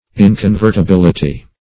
Inconvertibility \In`con*vert`i*bil"i*ty\, n. [L.